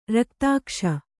♪ raktākṣa